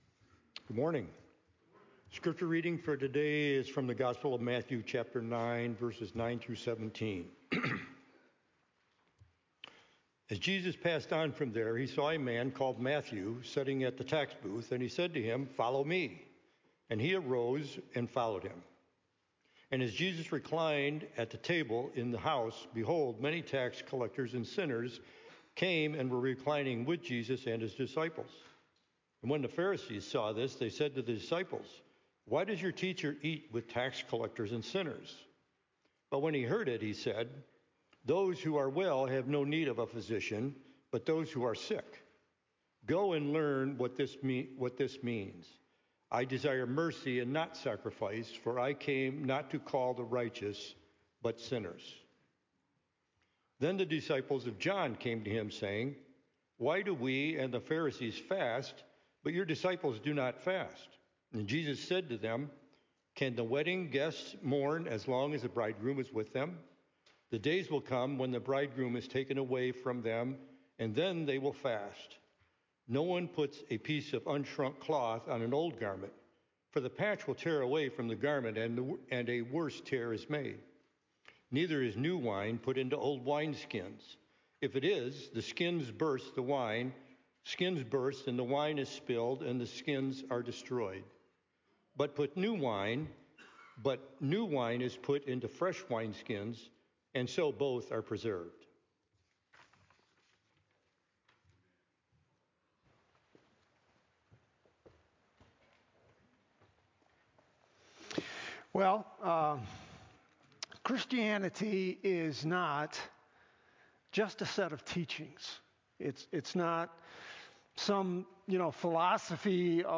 The King’s Authority to Command Lives – Berean Baptist Church